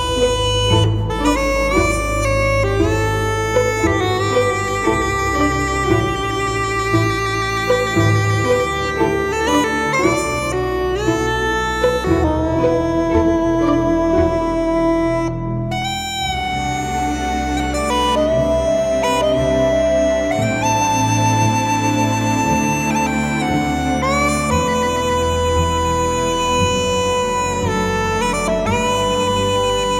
musique traditionnelle : Bretagne
Musique du Monde